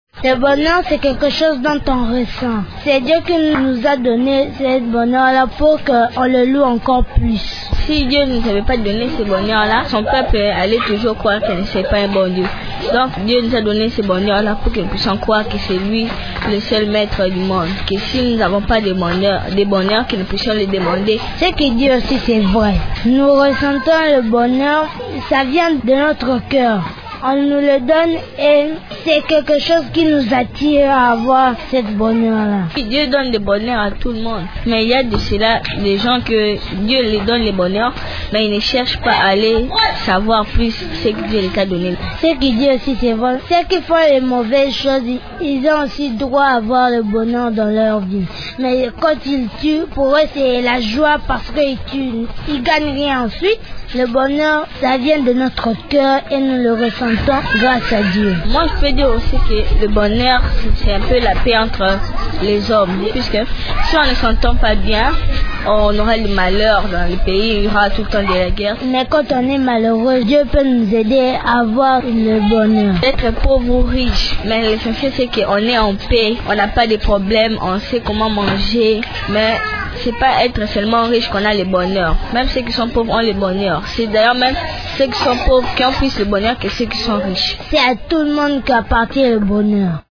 Les enfants parlent du bonheur. Ils pensent que le bonheur vient du cœur et que toute personne est attirée par le bonheur.